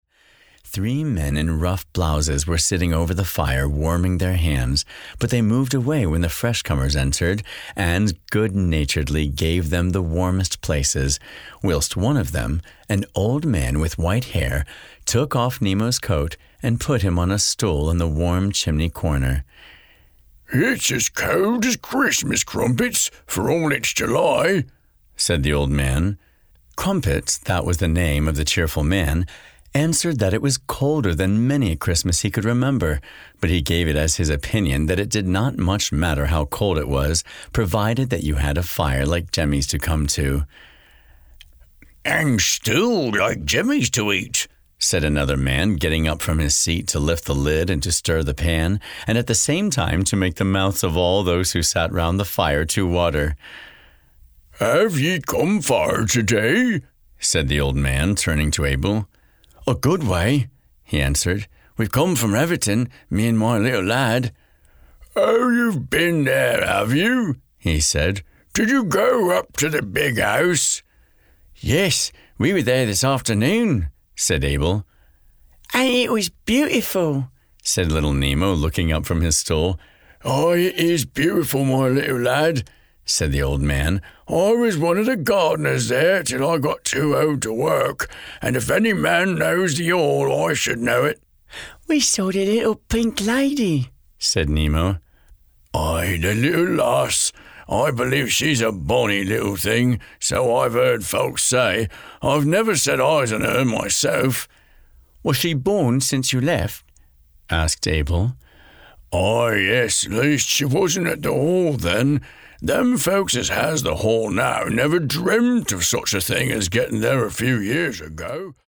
This is an audiobook, not a Lamplighter Theatre drama.
Wonderful Door Audiobook Sample.mp3